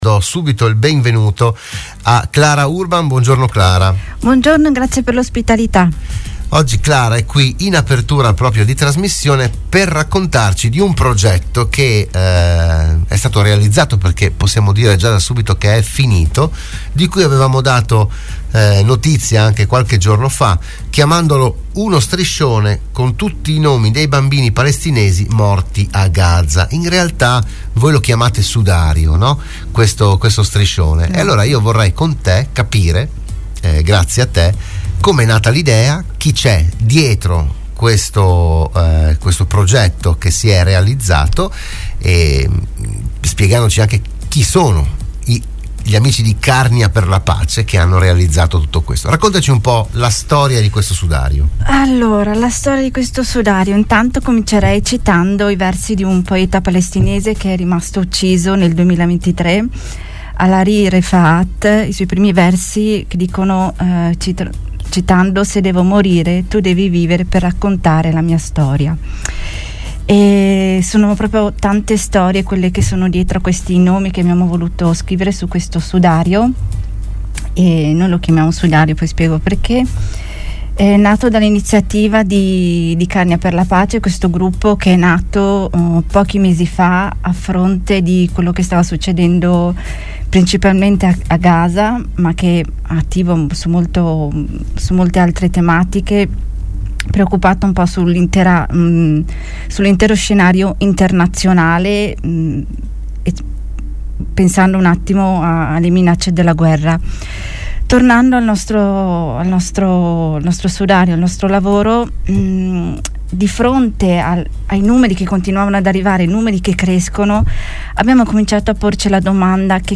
Dell’iniziativa si è parlato a “RadioAttiva“, la trasmissione di Radio Studio Nord